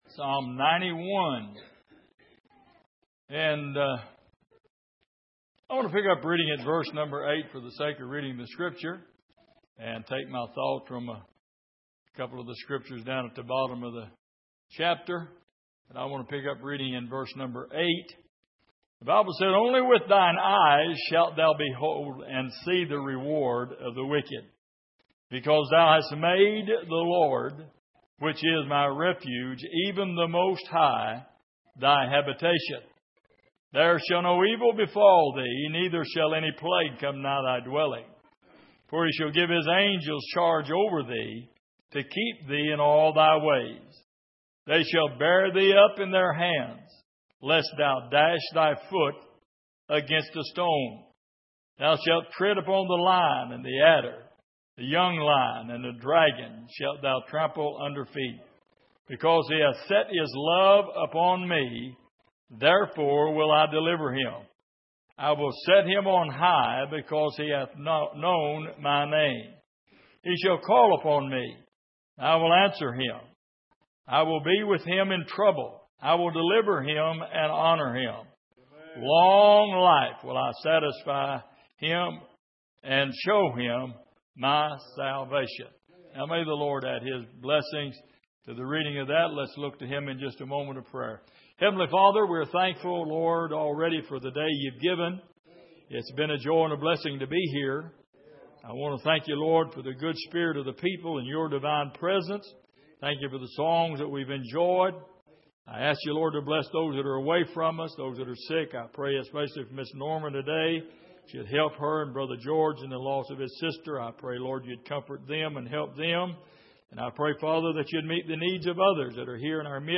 Passage: Psalm 91:8-16 Service: Sunday Morning